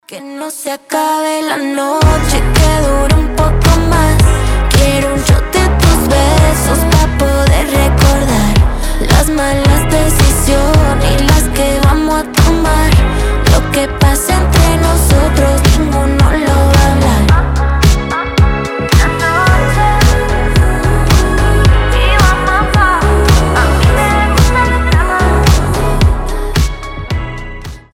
женский голос